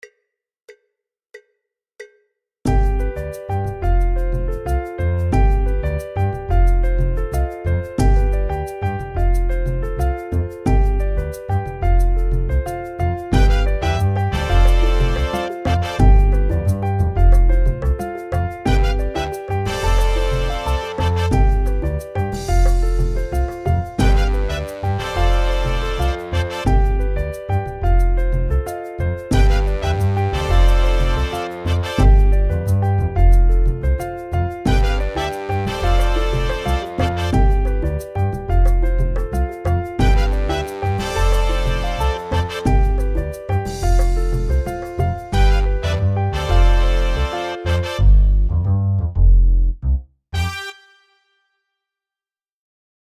Tonalidade: sol mixolidio; Compás 4/4